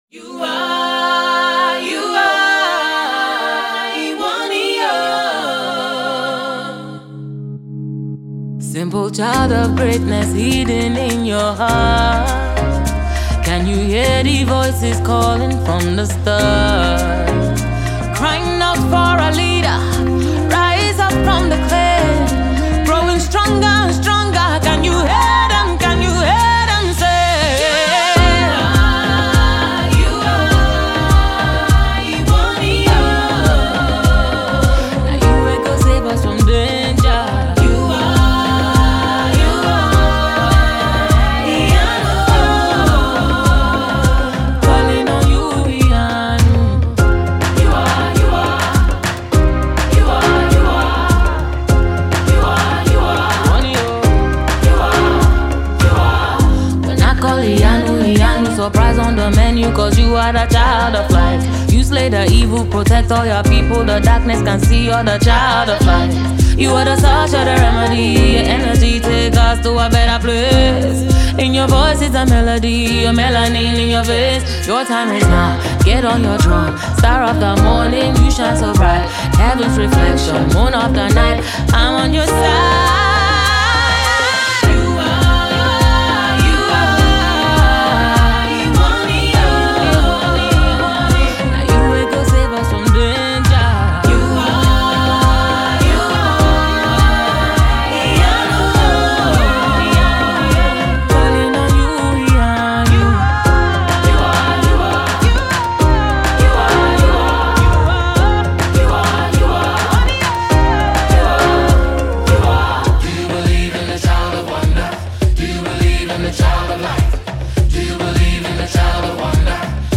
Nigerian Afro-pop queen
electrifying new track